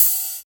78 OP HAT.wav